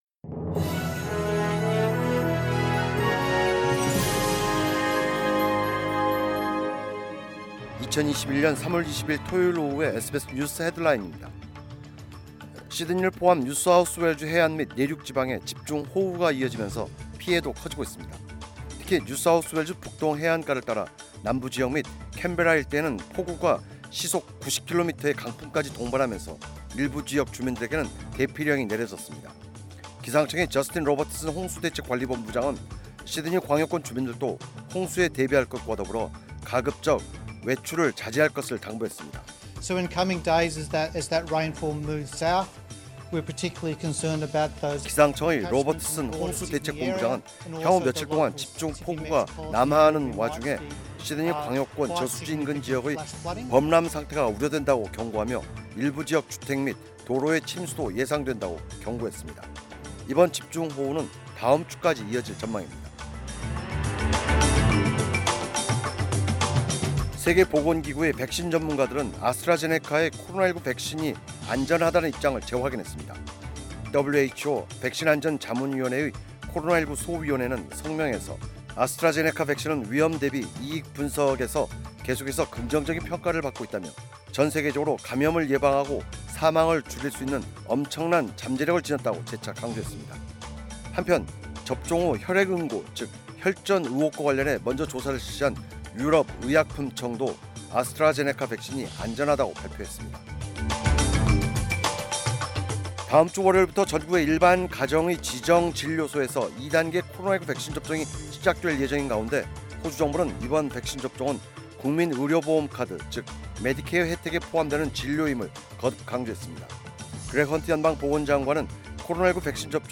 2021년 3월 20일 토요일 오후의 SBS 뉴스 헤드라인입니다.